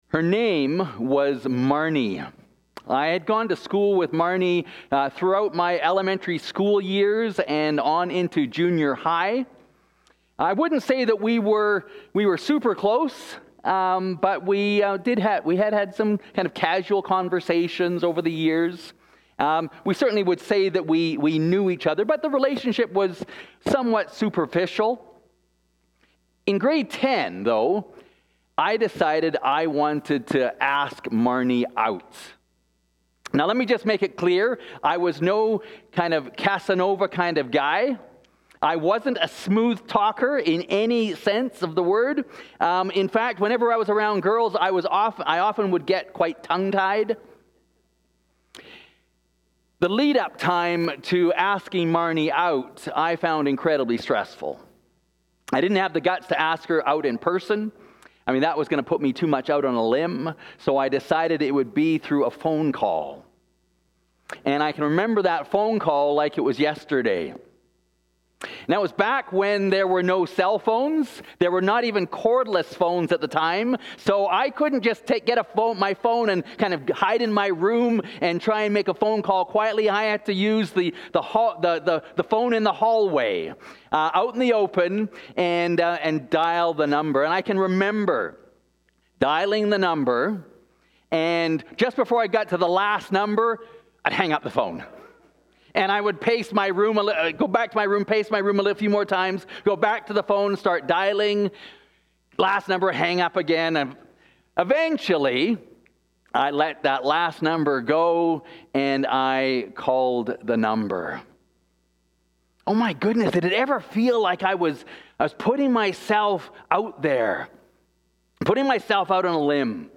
The Risk & the Gift of Relationship - Holy Trinity Anglican Church (Calgary)